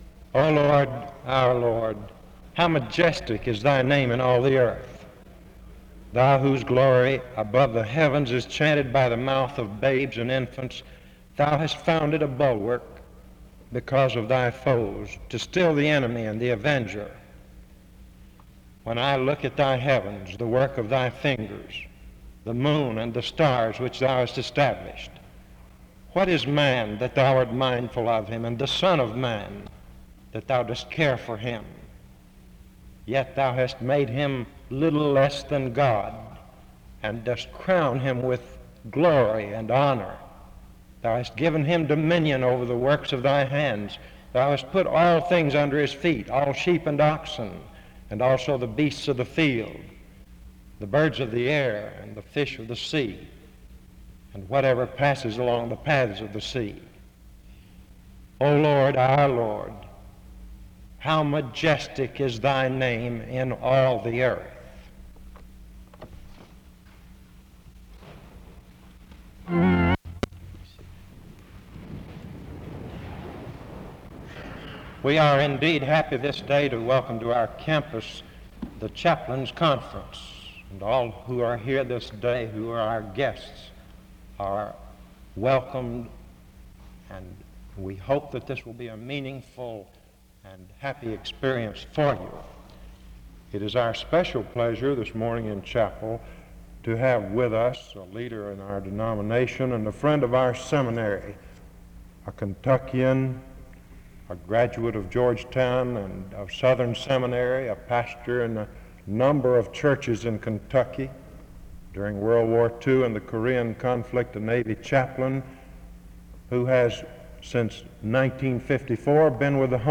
SEBTS Chapel
The service begins with a word of prayer from 0:00-1:06.
This service was the Chaplains' Day Conference.